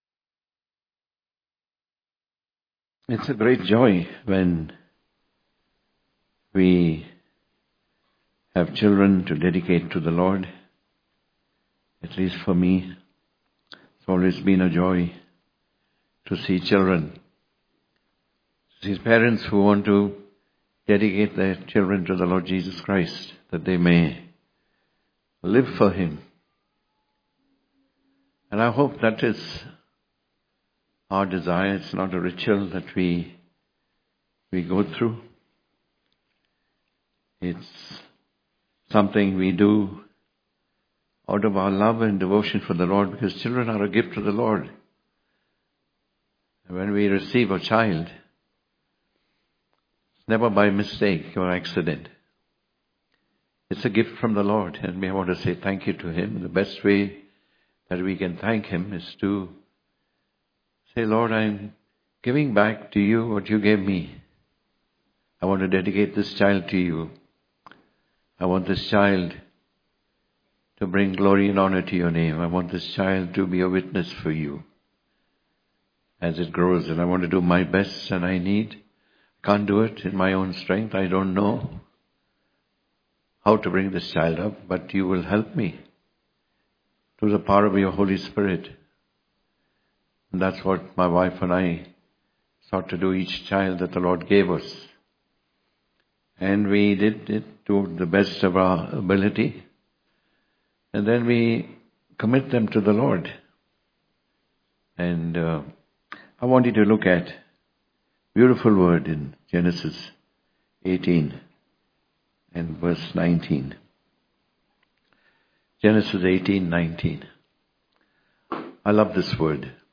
God Chooses Us To Bring Up Our Children In The Way Of The Lord Speaker